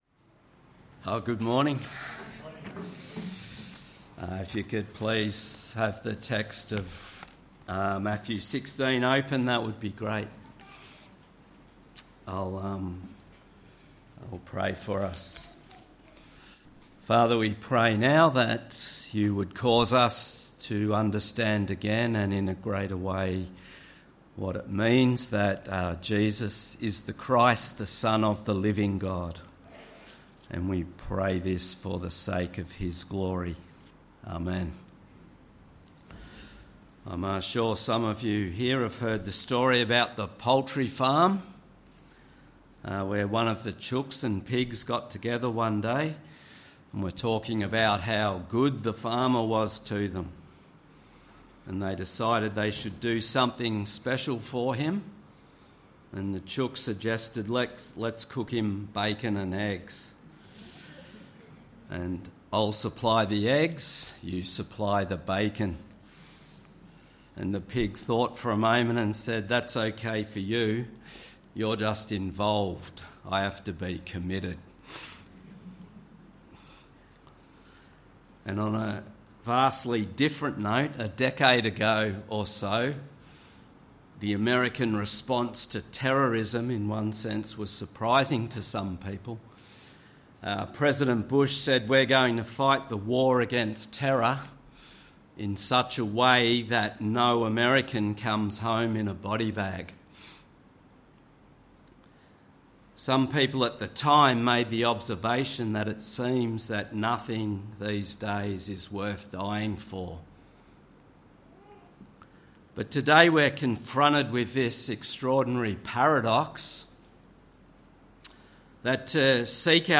Sermon: Matthew 16:21-28